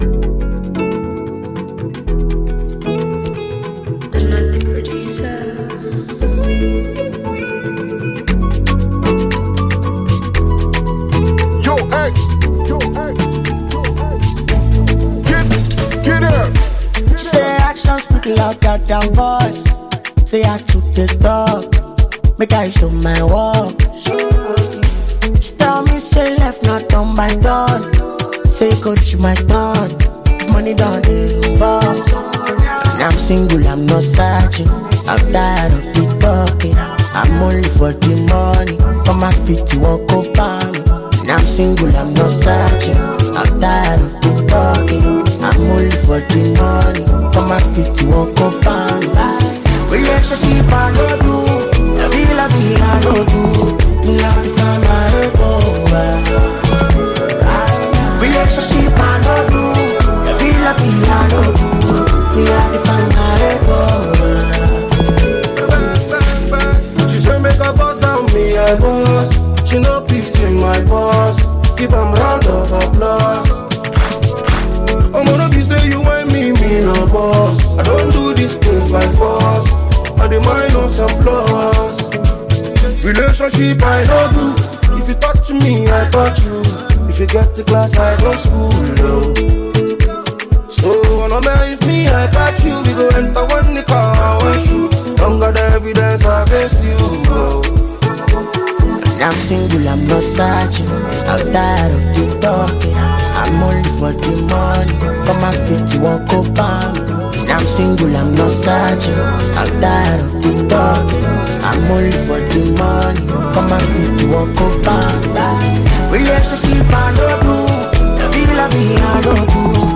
catchy song.